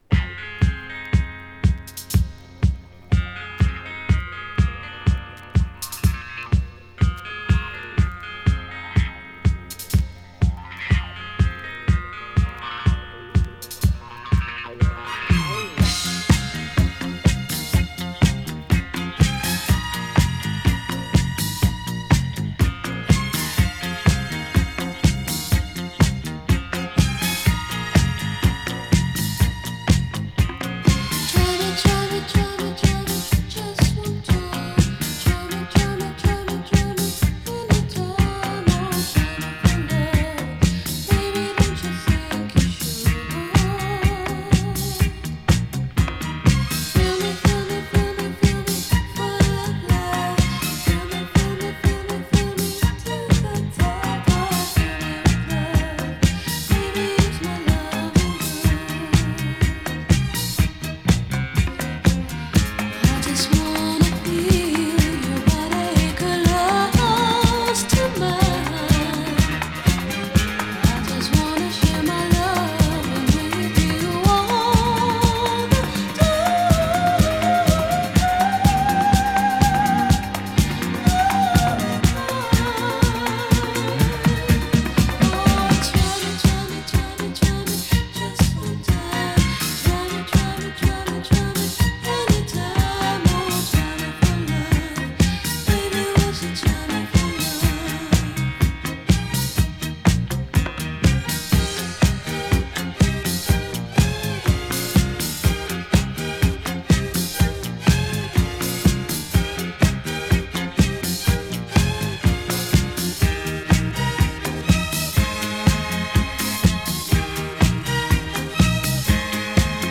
＊B面のイントロに軽いジリ・ノイズx1。